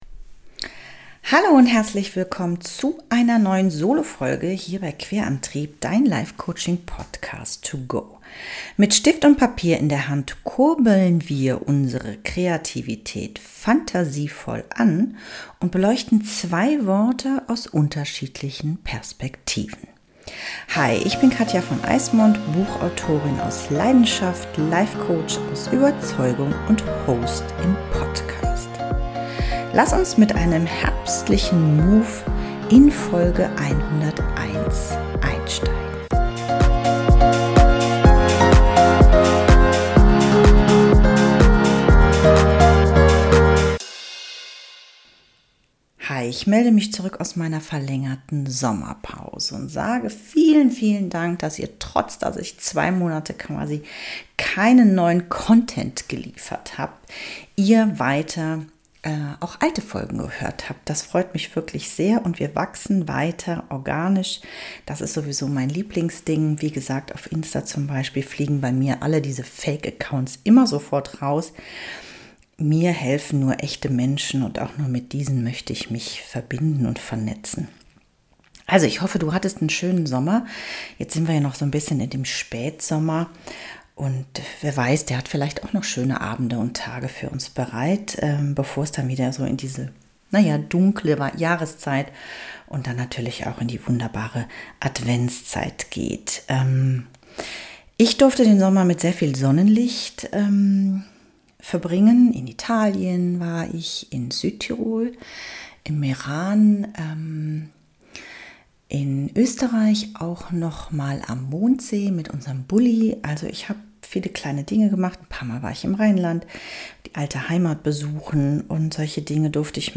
In dieser Solofolge kommst du direkt ins Tun. Mit Stift und Papier in der Hand kurbeln wir deine Kreativität fantasievoll an. Wir schauen uns an wie du deine Vorstellungkraft anregen kannst, um das was thematisch quer im Weg liegt anzustoßen.